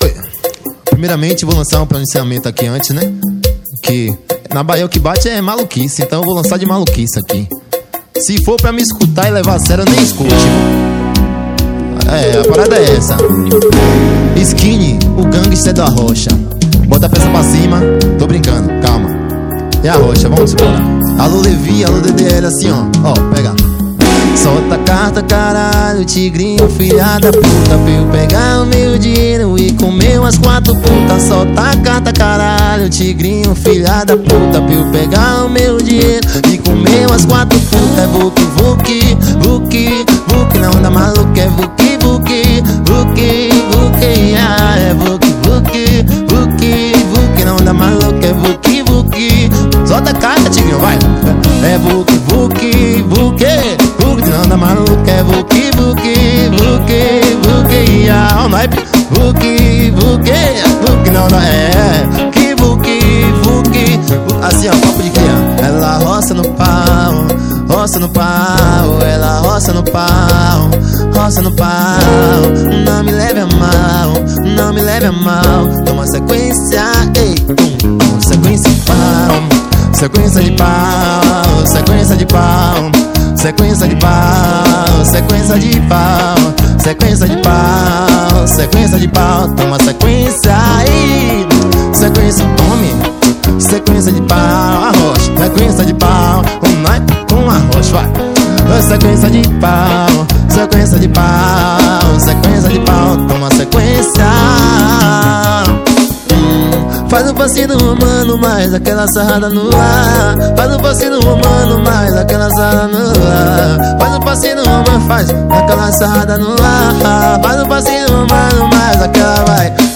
2024-12-23 20:01:08 Gênero: Arrocha Views